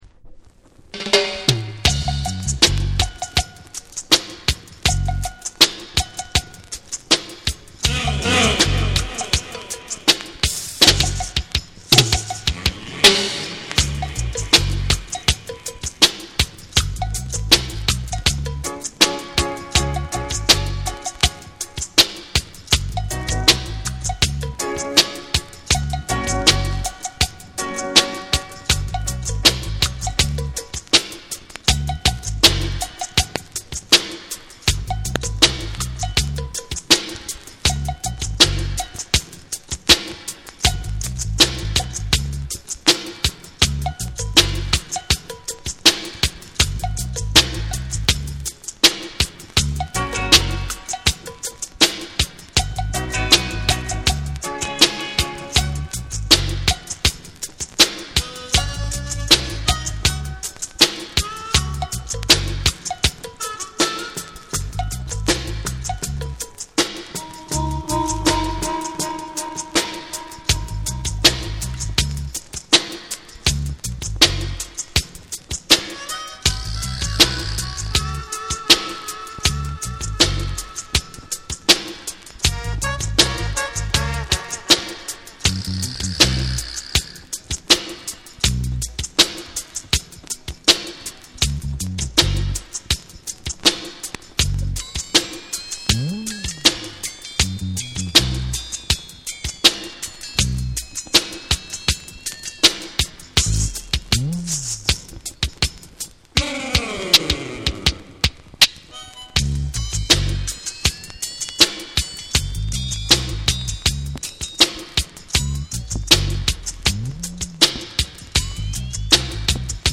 ※ジャマイカ盤特有のチリノイズが入ります。